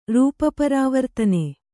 ♪ rūpa parāvartane